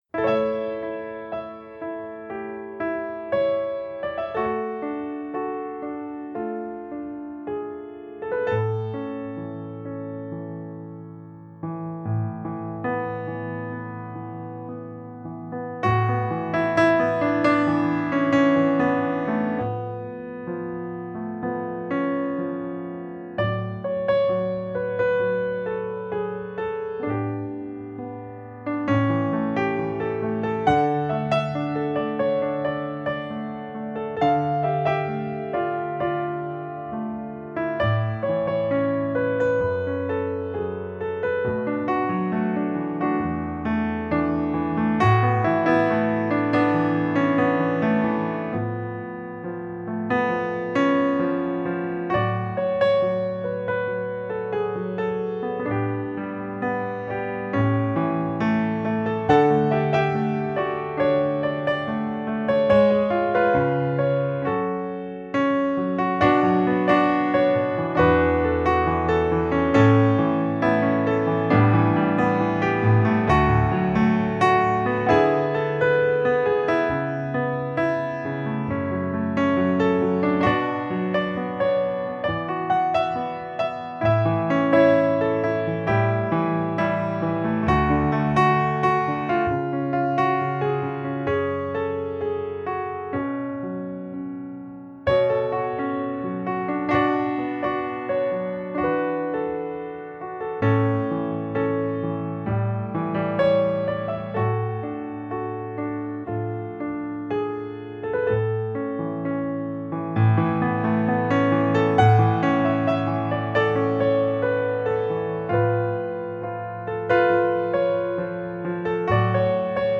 Genre: New Age, Instrumental, Piano.